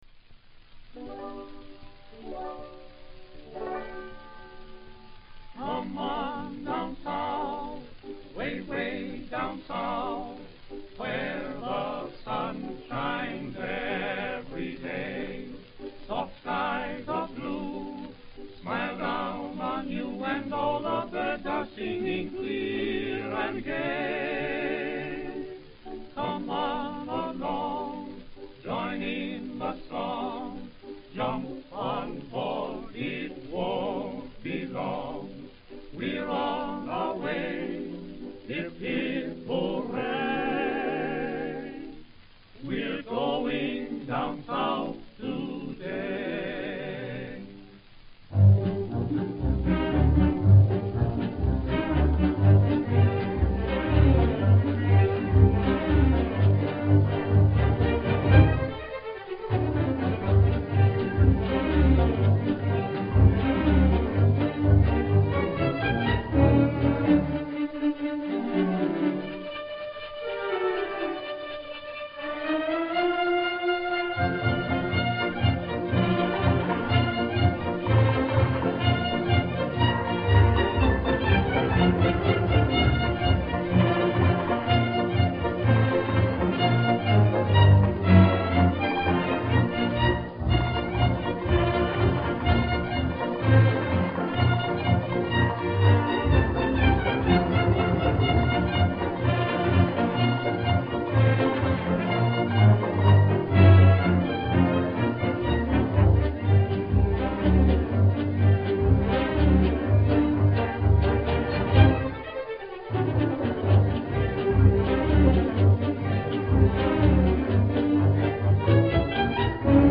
The brand also lent its name to what is seen as the first commercially sponsored variety show in broadcasting history.
But here's a snippet from the Eveready group, recorded in 1927.